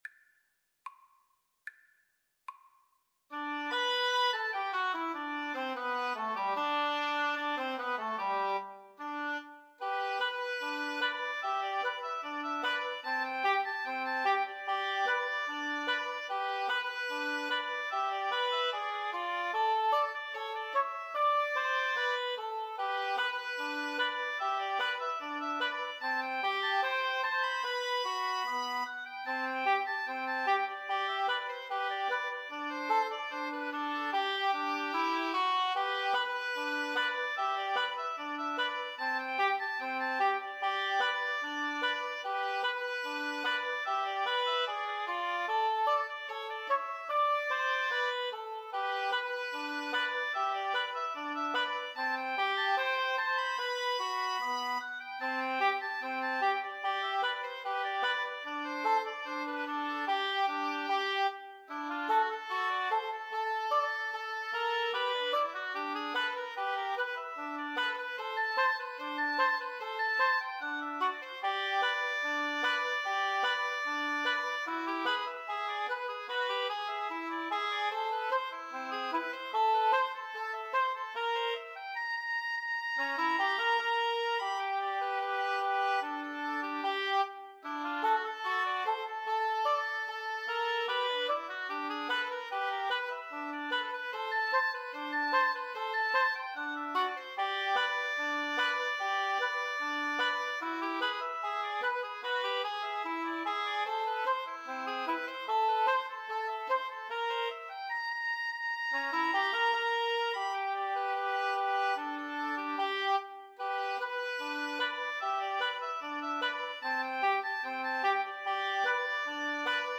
Oboe 1Oboe 2Cor Anglais
Not Fast = 74
2/4 (View more 2/4 Music)
Jazz (View more Jazz Woodwind Trio Music)